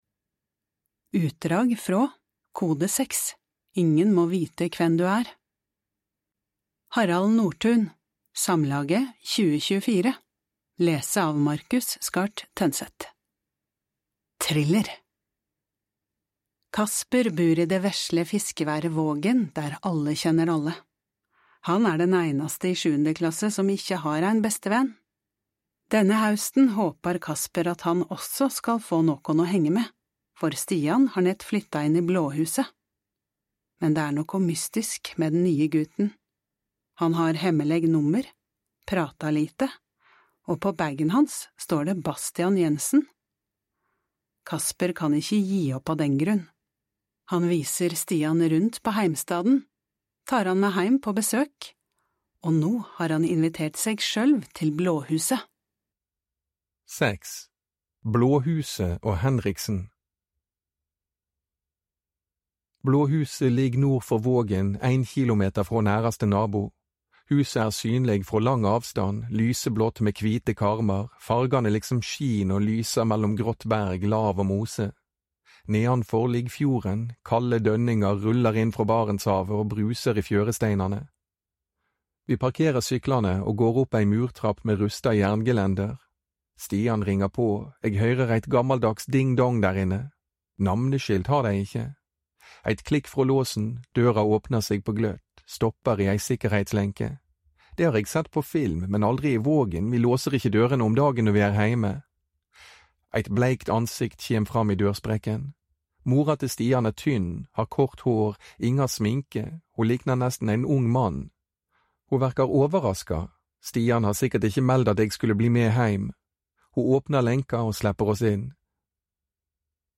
Lån heile lydboka frå Tibi! Kasper bur i det vesle fiskeværet Vågen der alle kjenner alle.